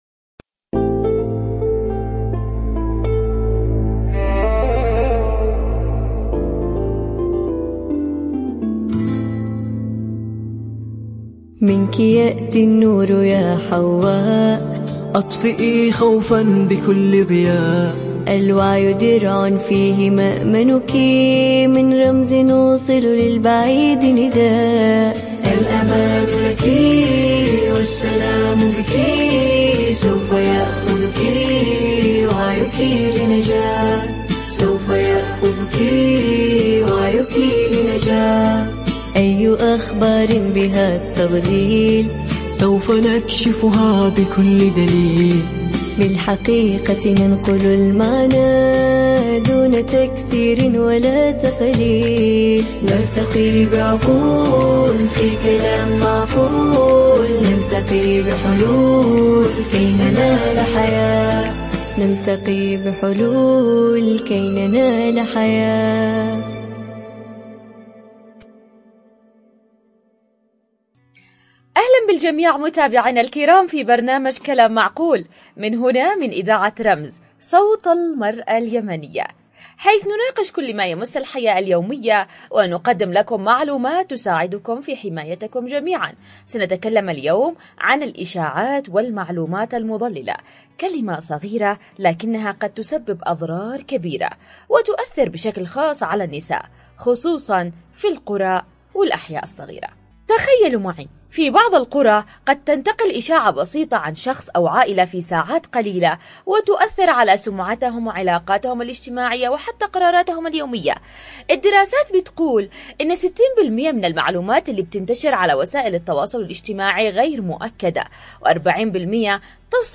عبر أثير إذاعة رمز